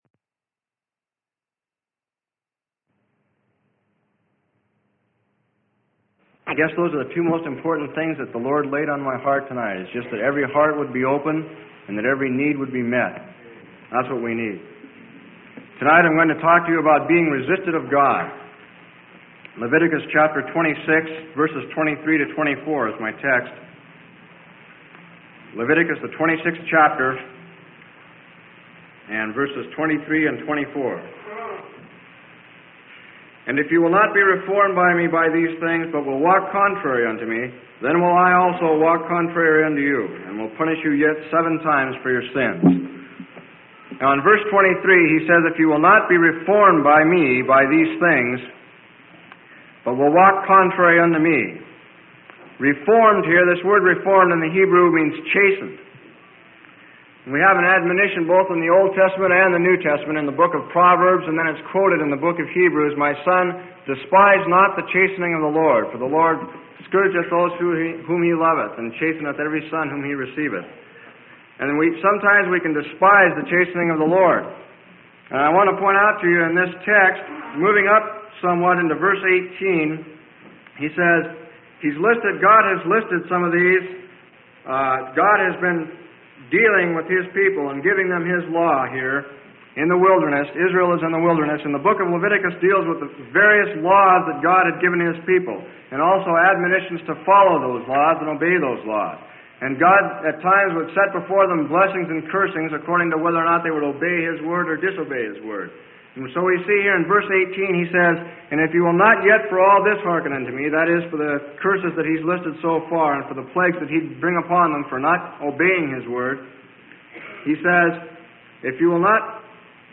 Sermon: Resisted of God - Freely Given Online Library